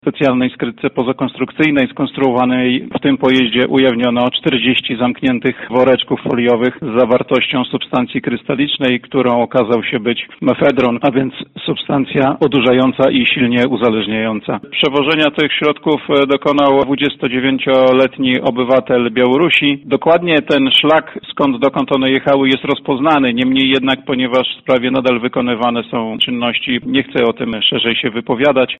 – Czarnorynkowa wartość substancji odurzających to ponad 2,5 miliona złotych – mówi szef Prokuratury Okręgowej w Siedlcach, prokurator Krzysztof Czyżewski.